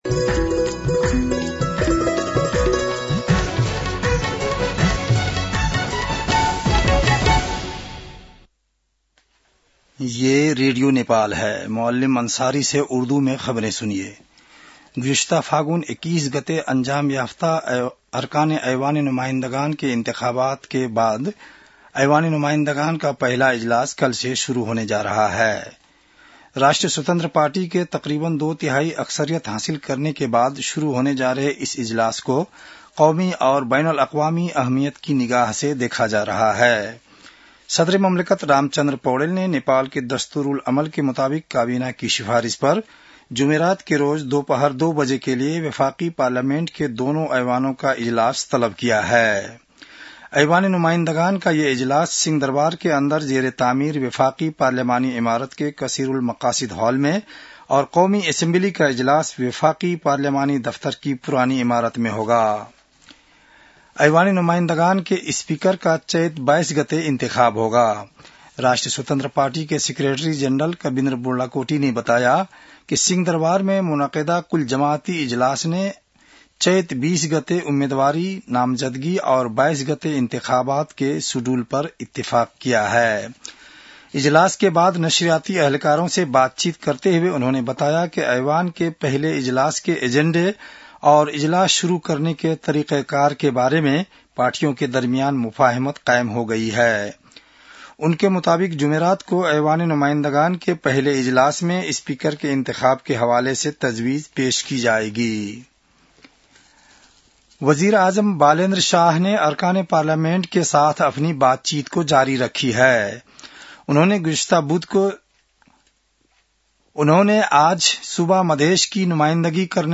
उर्दु भाषामा समाचार : १८ चैत , २०८२